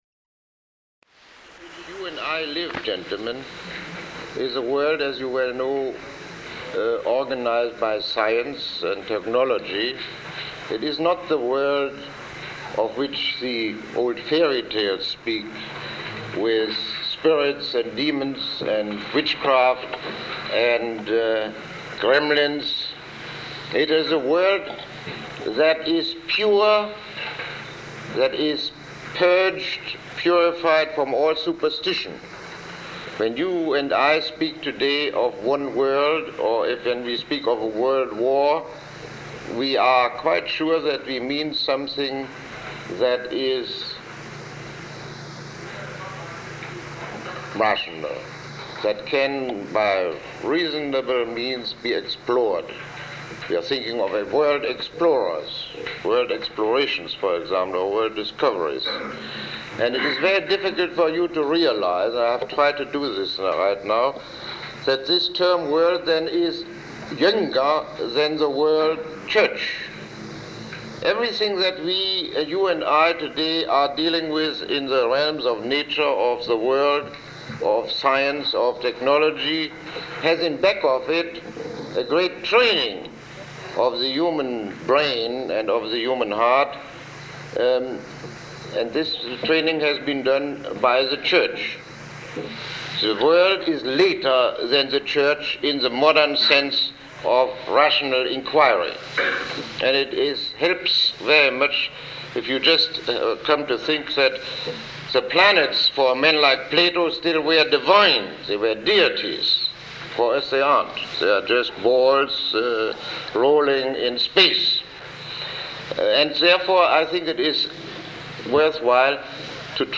Lecture 27